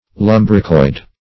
Search Result for " lumbricoid" : The Collaborative International Dictionary of English v.0.48: Lumbricoid \Lum"bri*coid\, a. [Lumbricus + -oid.]